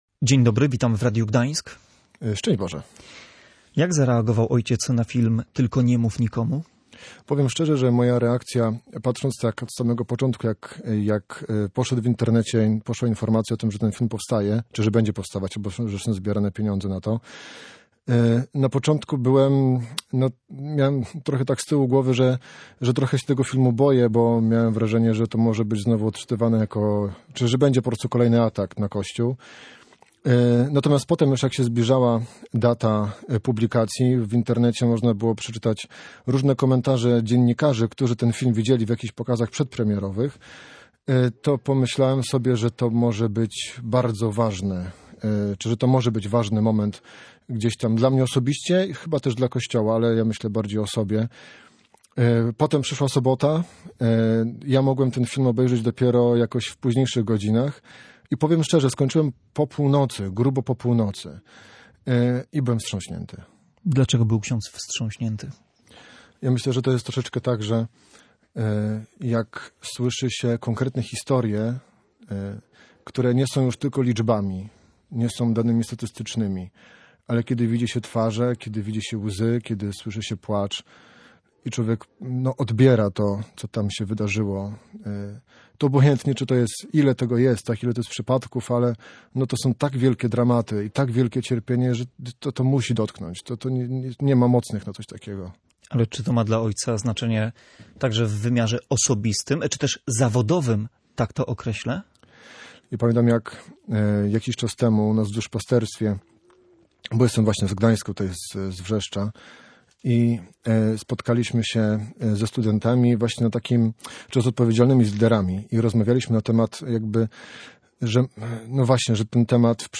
audycje Gość Dnia Radia Gdańsk